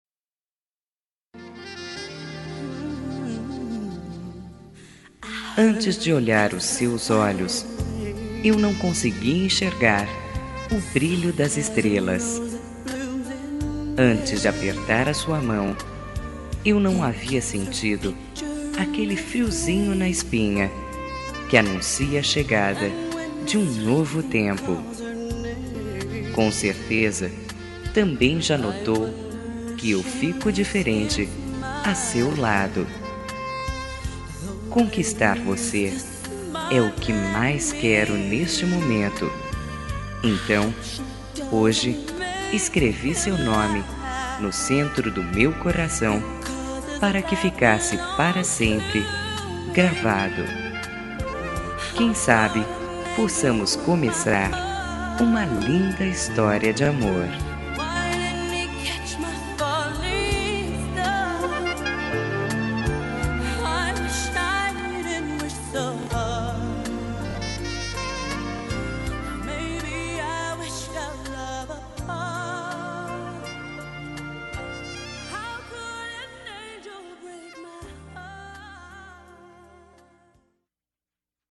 Telemensagem de Paquera – Voz Feminina – Cód: 2142